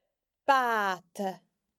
Listen for the à sound in this Gaelic word: